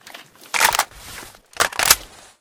ak74_reload.ogg